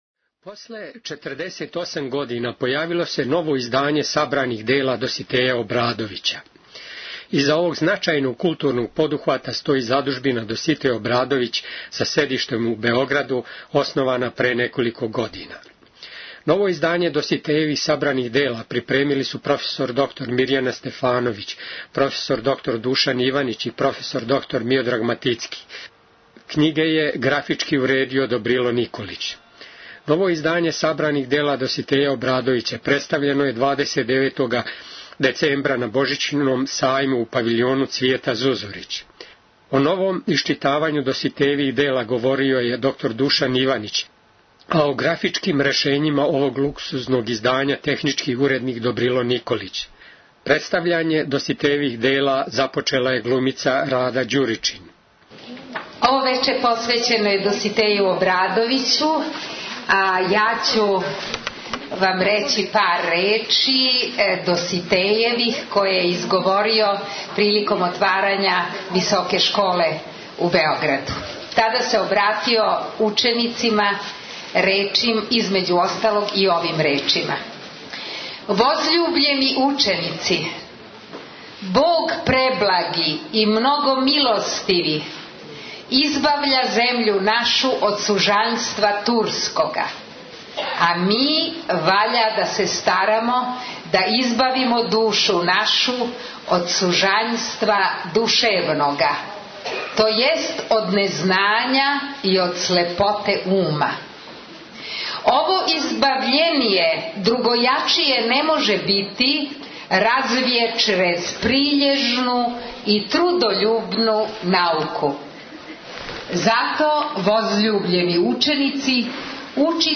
Сабрана дјела Доситеја Обрадовића Tagged: Извјештаји
Ново издање сабраних дјела Доситеја Обрадовића представљено је 29. децембра на Божићном сајму у Павиљону Цвијета Зузорић.